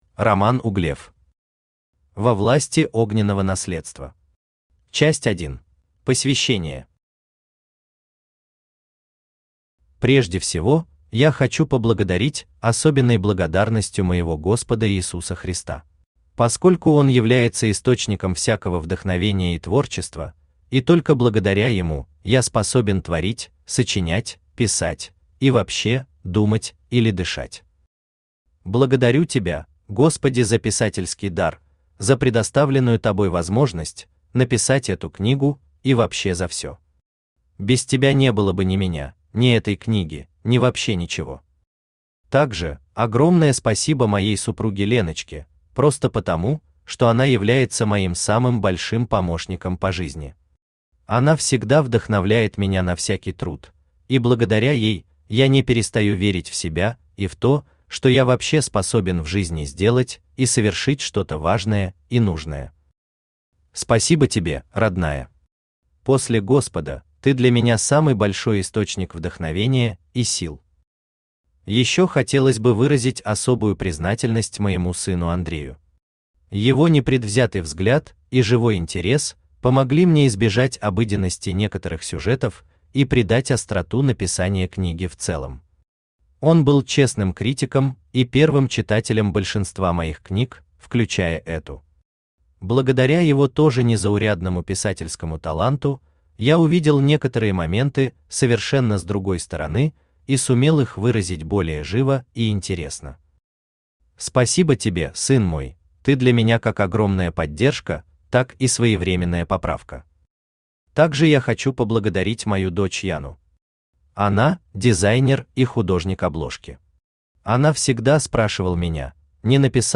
Аудиокнига Во власти огненного наследства. Часть 1 | Библиотека аудиокниг
Часть 1 Автор Роман Романович Углев Читает аудиокнигу Авточтец ЛитРес.